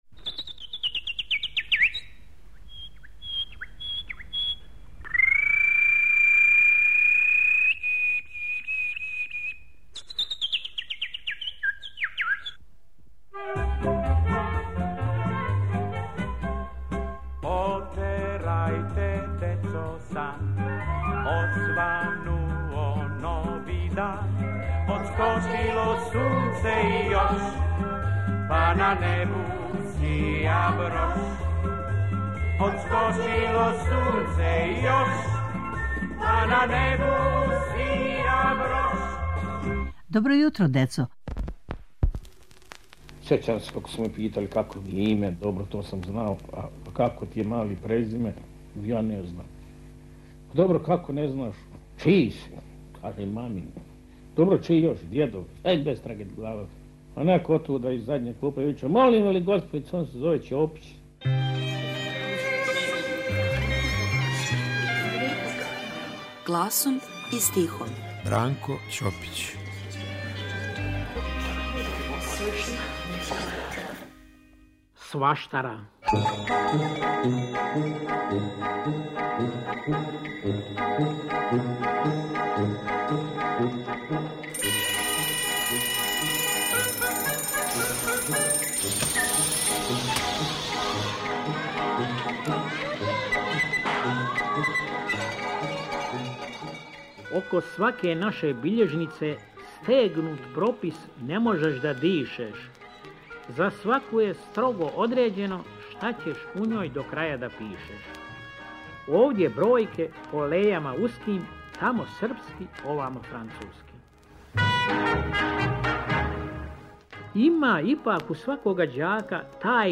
У серијалу "Гласом и стихом" - из старе фиоке архиве Радио Београда, гласом и стихом, јављају се чувени песници за децу. Ово је јединствена прилика за слушаоце јер су у питању аутентични звучни записи које само Радио Београд чува у својој архиви. Ове недеље - Бранко Ћопић.